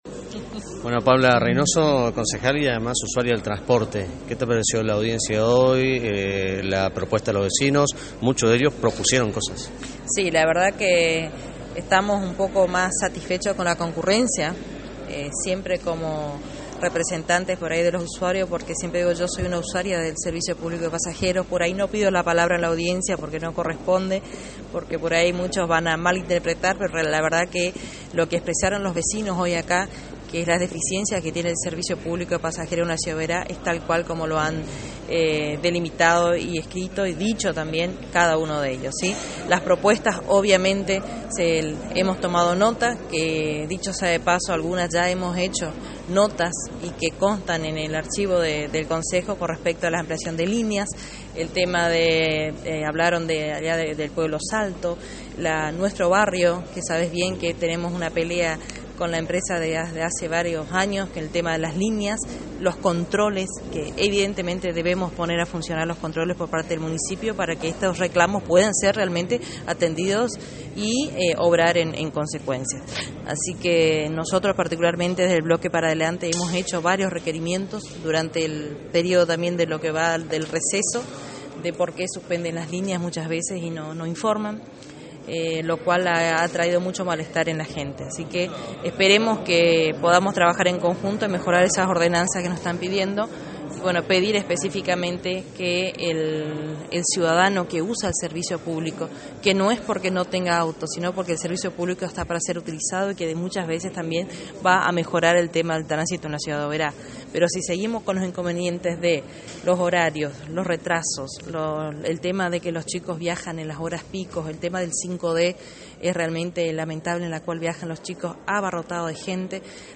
La declaraciones de la concejal de Oberá, Paula Reinoso, fue luego de la audiencia pública sobre el servicio del transporte público, que brinda la empresa Capital del Monte y había pedido un aumento del boleto del 100 % para el boleto único.
Audio: Paula Reinoso, concejal de Oberá